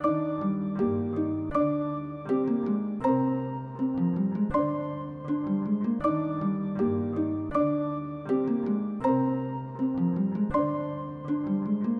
Tag: 160 bpm Trap Loops Piano Loops 2.02 MB wav Key : D